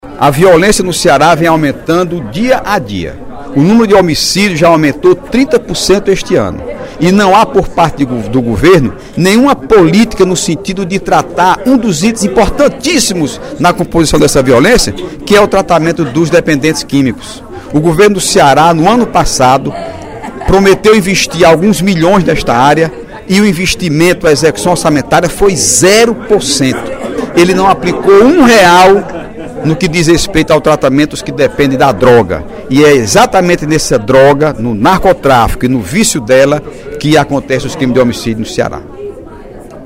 O deputado Heitor Férrer (PDT) criticou, nesta terça-feira (11/06), durante o primeiro expediente da sessão plenária, a violência no Ceará.